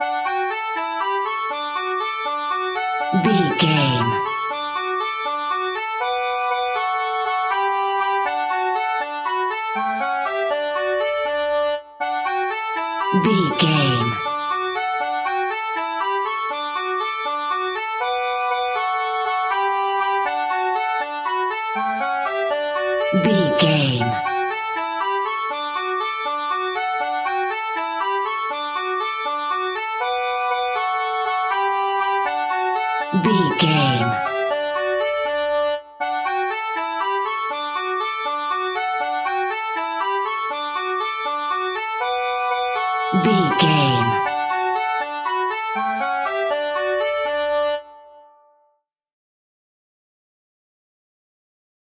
Ionian/Major
D
Fast
nursery rhymes
kids music